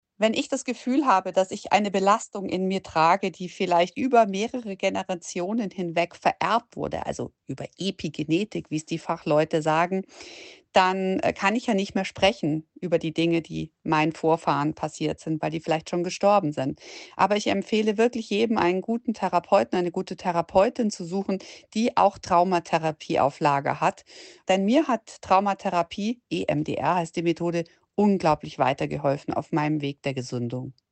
Wir sprechen mit Caro Matzko, Journalistin und TV-Moderatorin.